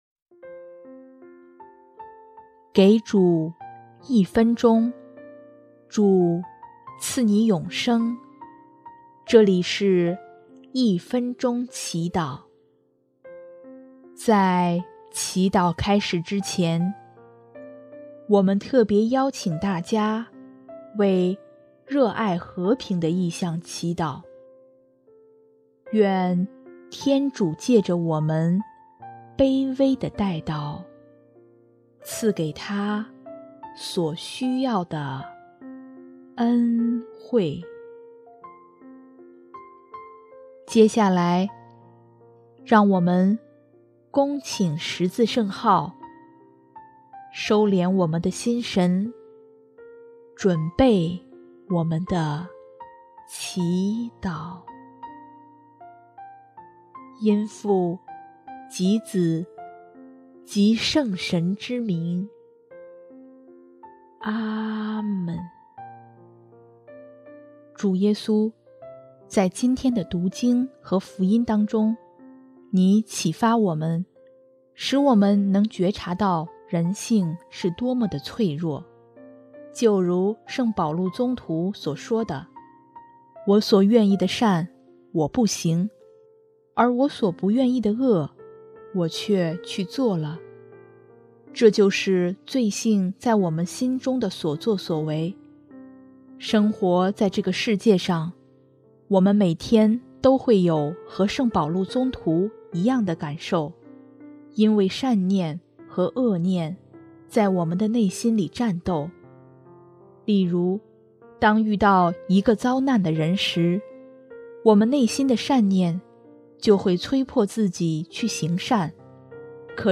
【一分钟祈祷】|10月28号 主耶稣彻夜祈祷，我们应常依赖祂！
音乐：主日赞歌《凡求的就必得到》（热爱和平Love love peace：为世界和平，罪人悔改）